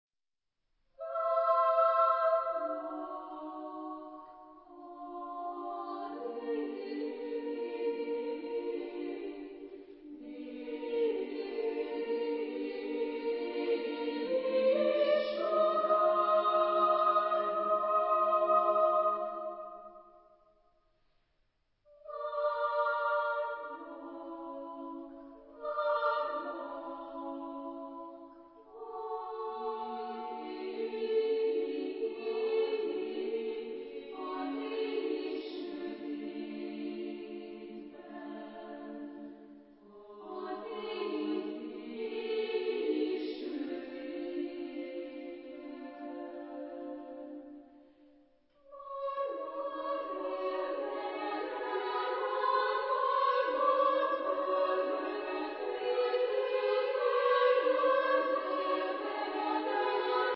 Type of Choir: SMA  (3 equal voices )
Tonality: Tonal centers